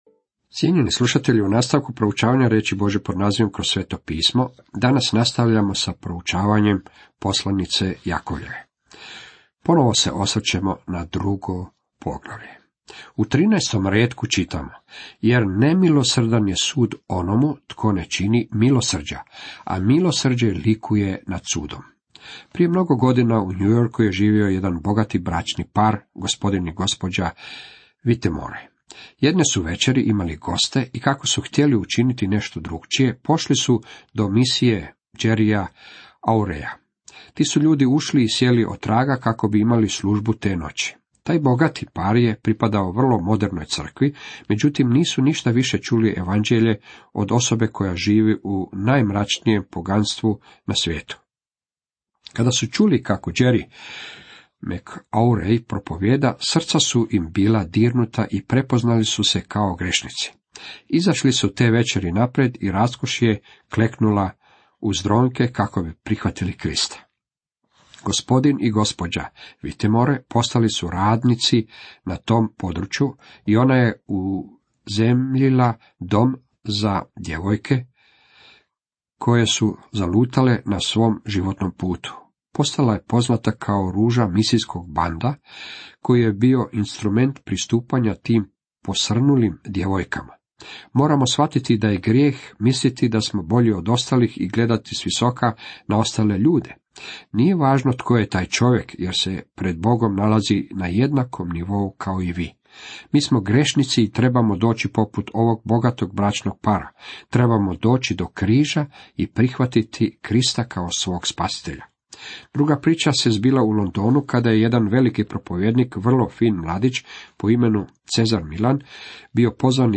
Program je sačinjen kako bi bio 30 minutni radijski program koji sistematski vodi slušatelja kroz cijelu Bibliju.